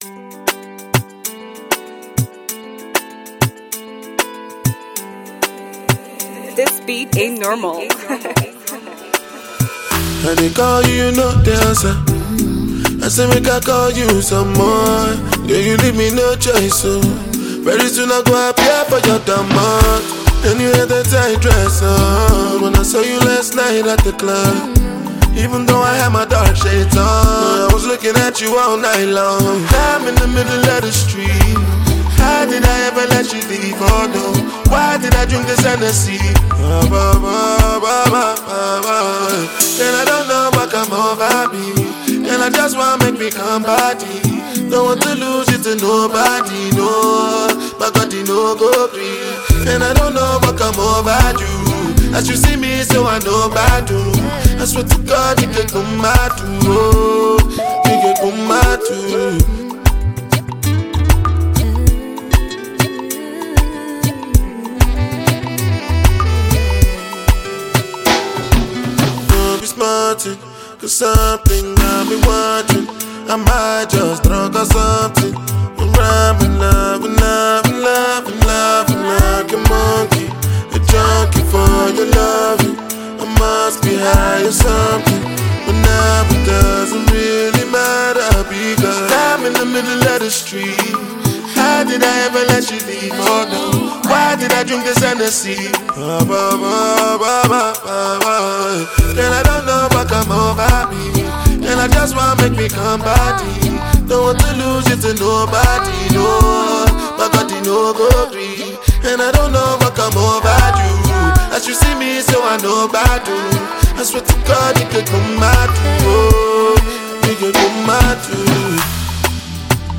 Released in 2019, it mixes Afrobeat, reggae, and hip-hop.